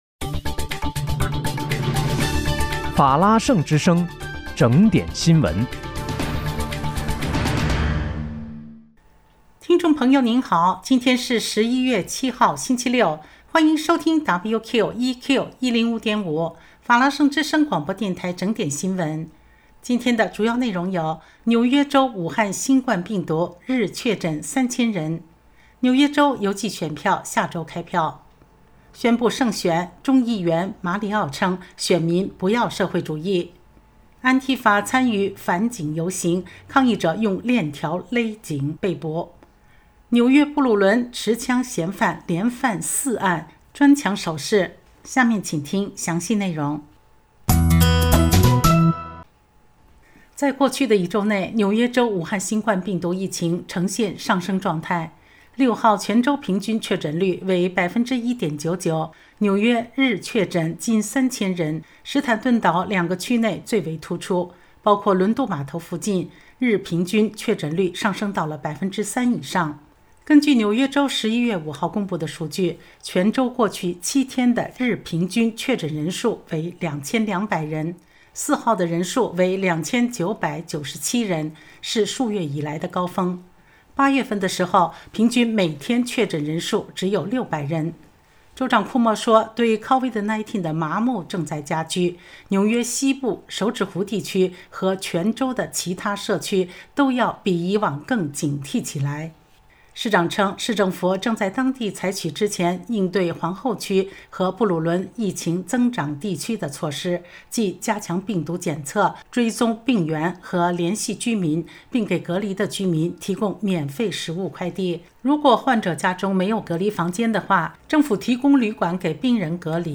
11月7日（星期六）纽约整点新闻